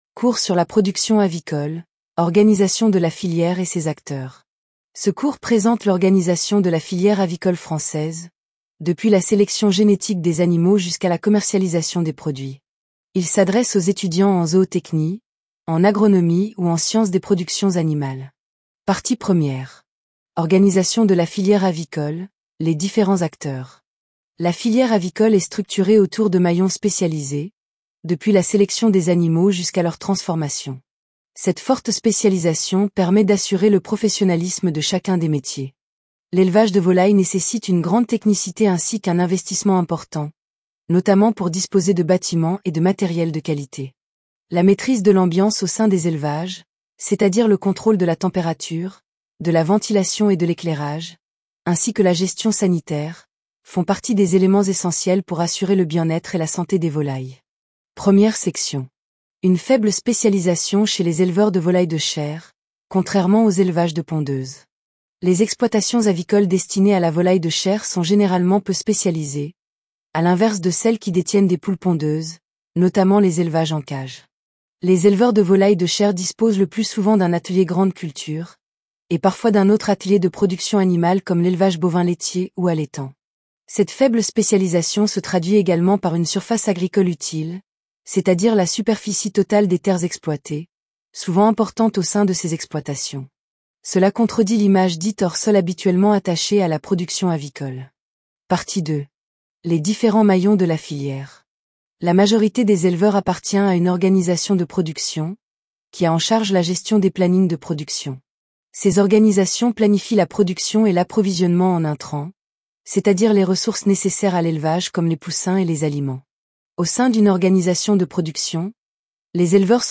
Podcast : Production Avicole Tts
PRODUCTION_AVICOLE_TTS.mp3